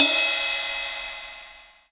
Ride Cym 1.wav